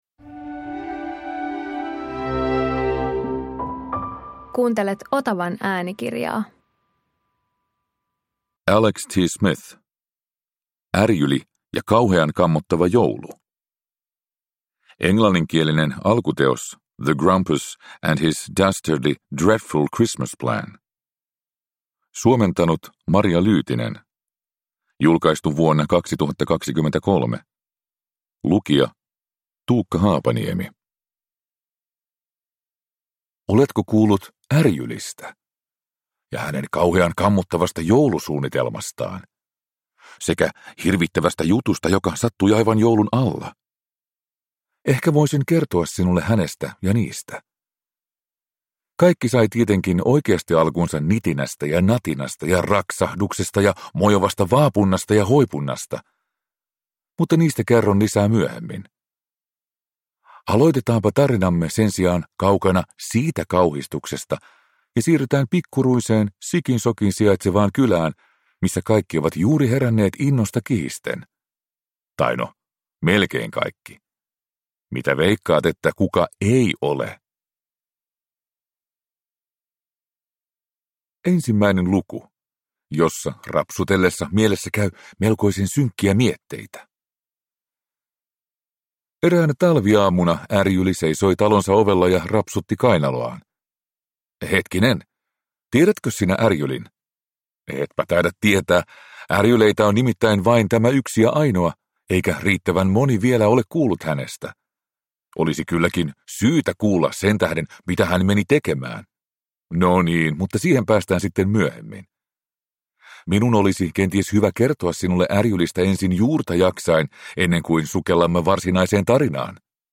Ärjyli ja kauhean kammottava joulu – Ljudbok – Laddas ner